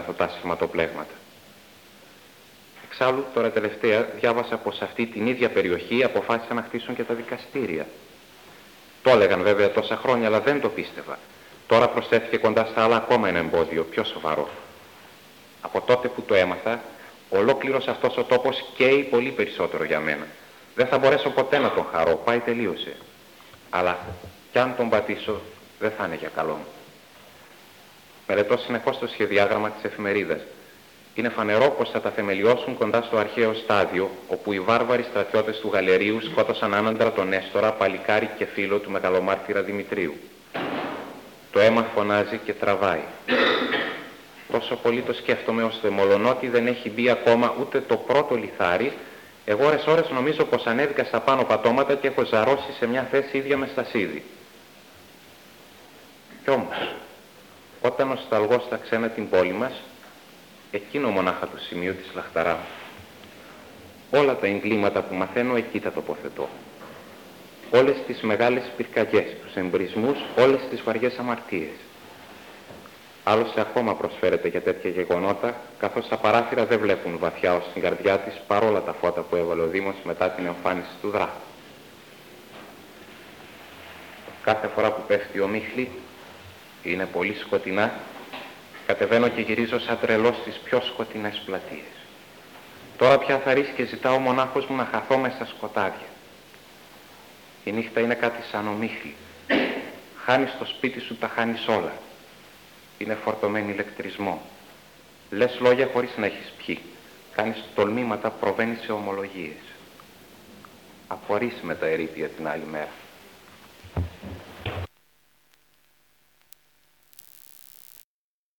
Εξειδίκευση τύπου : Εκδήλωση
Εμφανίζεται στις Ομάδες Τεκμηρίων:Εκδηλώσεις λόγου